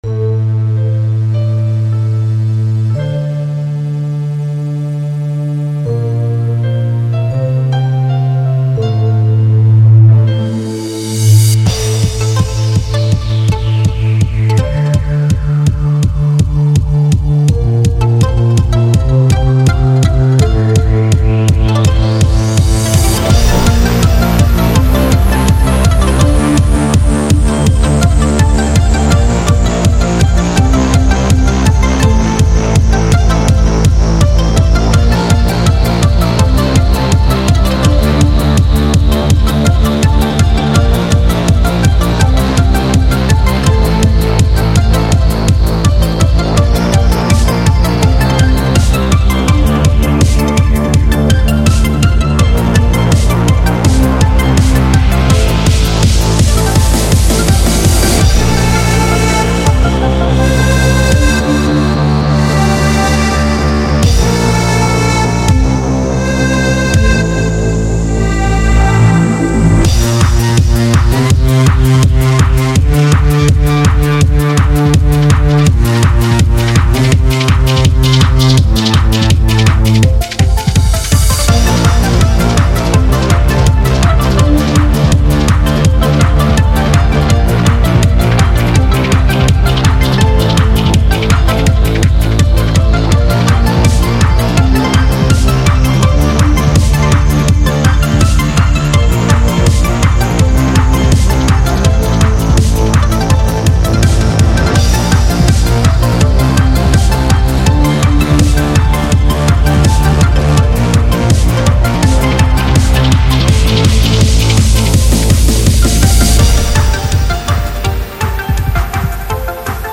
EDM
absorbing the original piano elements